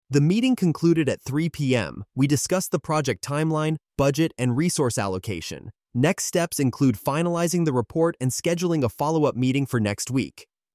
someone-speaking.mp3